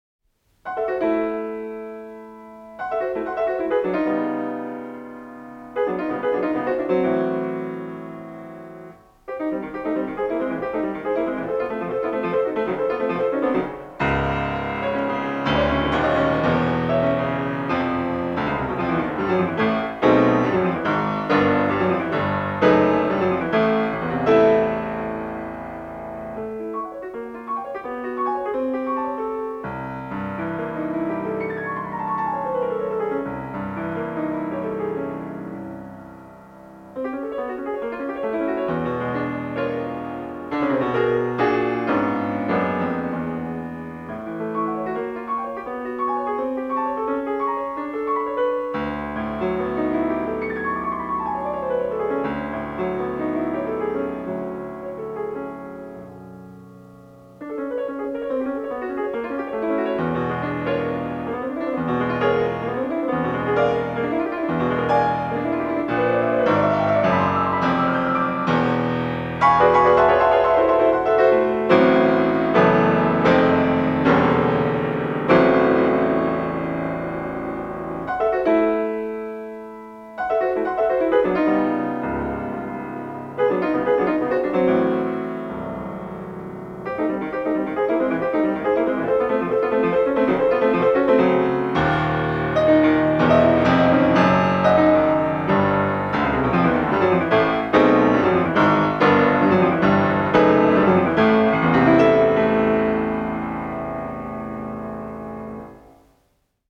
Kamermūzika
Instrumentāls skaņdarbs